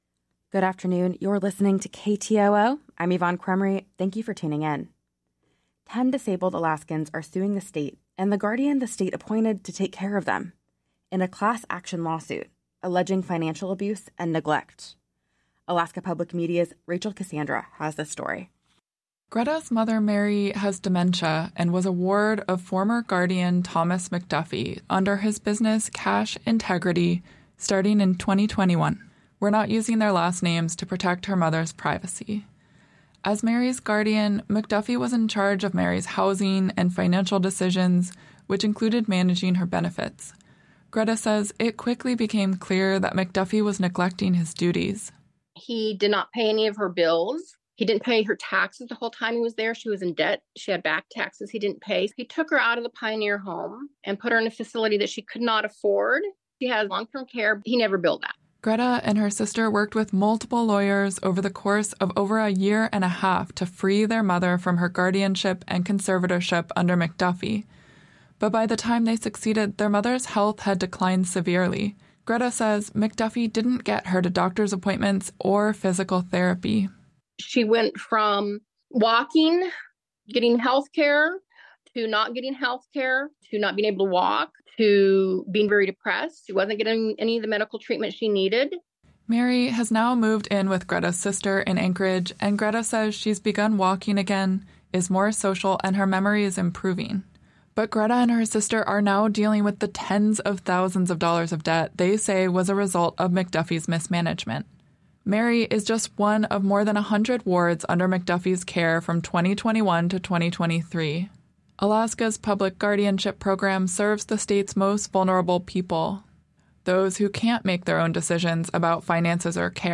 Newscast – Tuesday, August 13, 2024